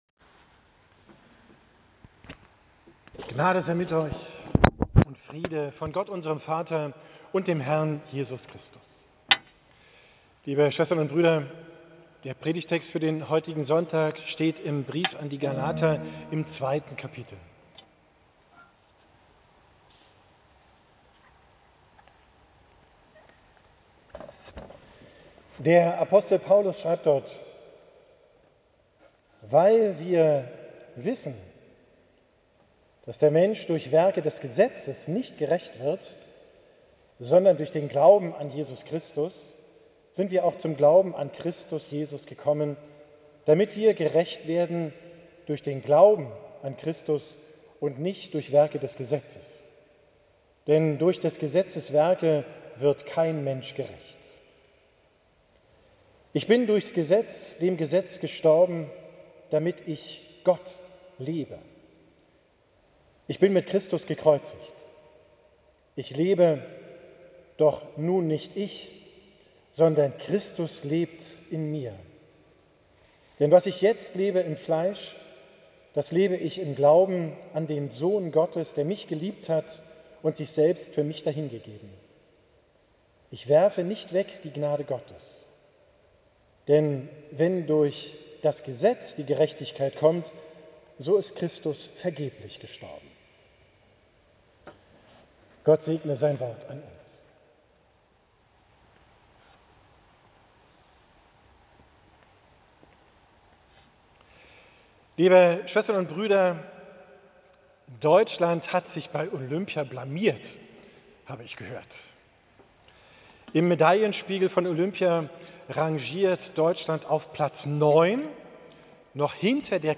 Predigt vom 11.